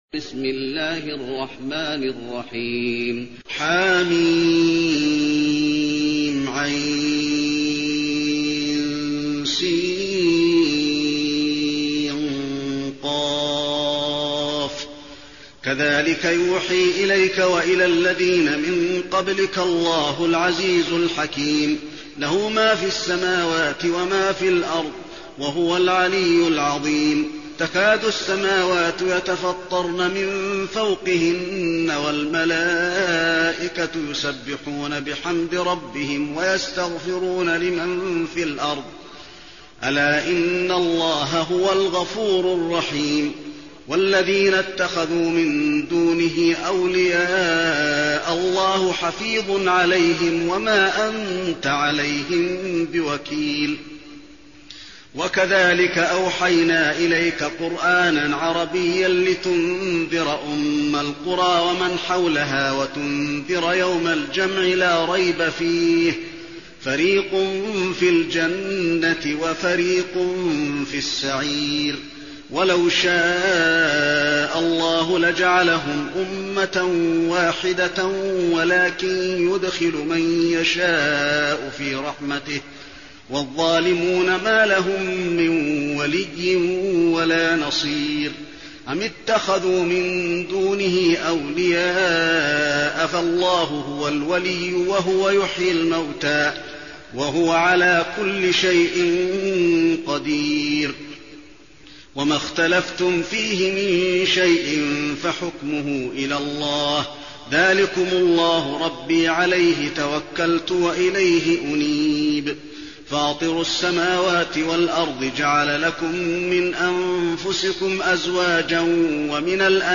المكان: المسجد النبوي الشورى